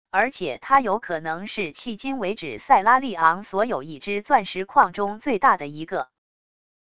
而且它有可能是迄今为止塞拉利昂所有已知钻石矿中最大的一个wav音频生成系统WAV Audio Player